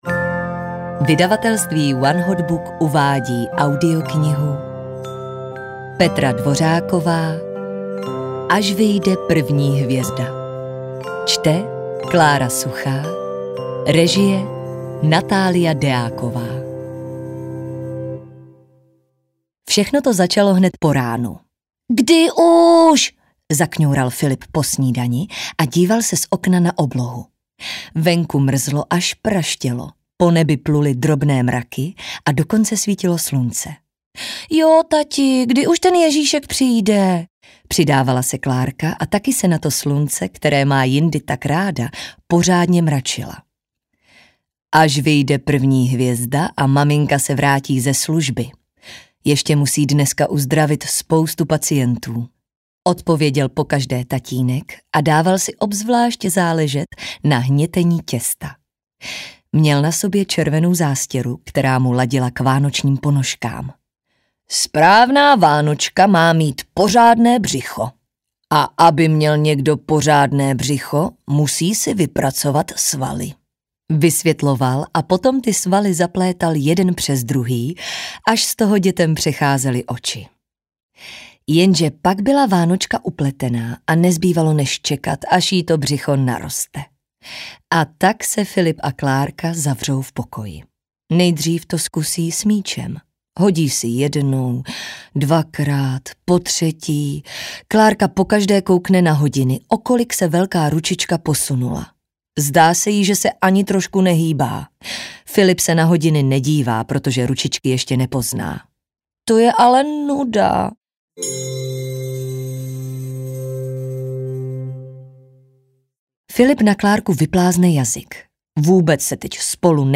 Až vyjde první hvězda audiokniha
Ukázka z knihy
az-vyjde-prvni-hvezda-audiokniha